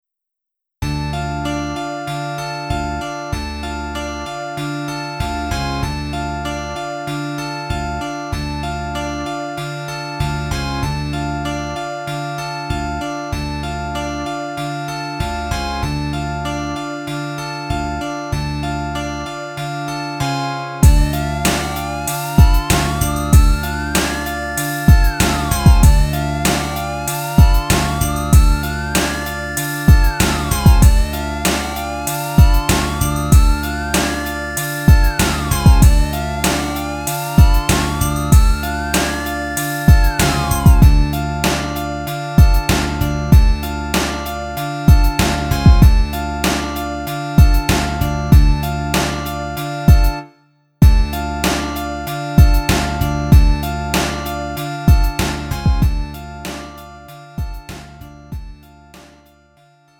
음정 원키 3:24
장르 가요 구분 Lite MR
Lite MR은 저렴한 가격에 간단한 연습이나 취미용으로 활용할 수 있는 가벼운 반주입니다.